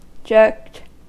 Ääntäminen
Ääntäminen US Haettu sana löytyi näillä lähdekielillä: englanti Käännöksiä ei löytynyt valitulle kohdekielelle. Jerked on sanan jerk partisiipin perfekti.